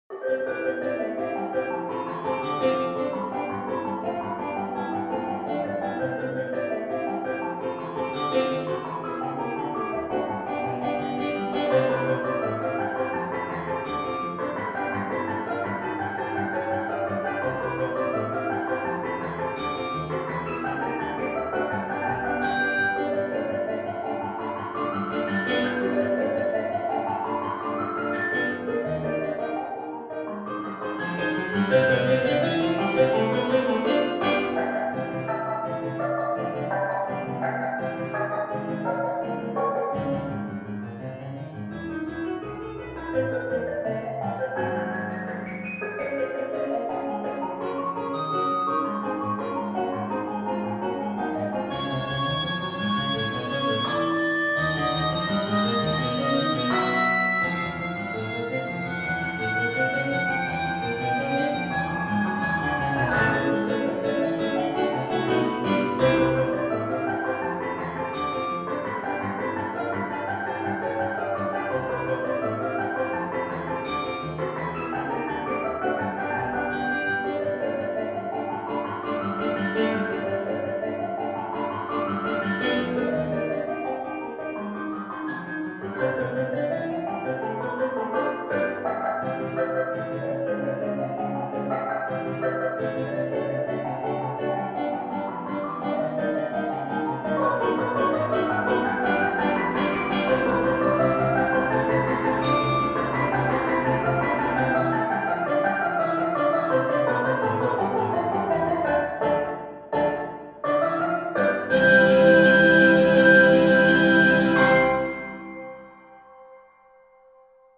will be a show-stopper for the advanced duet ringers.